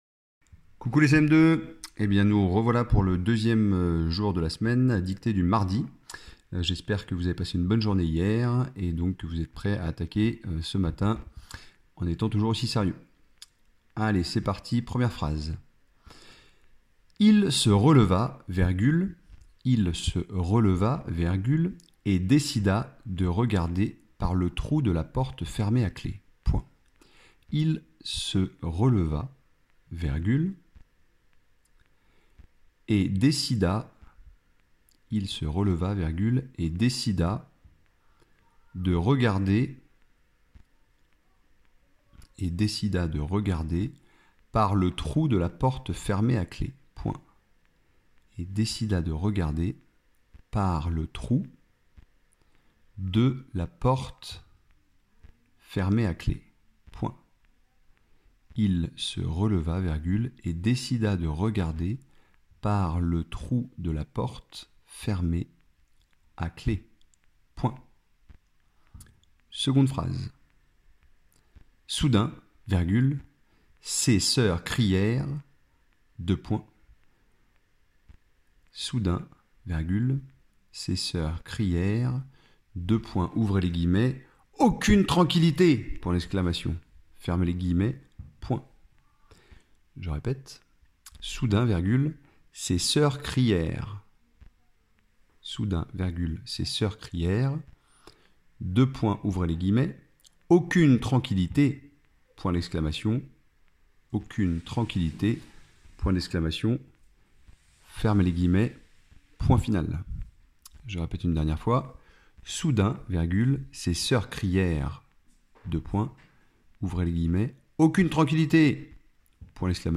Dictée du JOUR Mardi 27 avril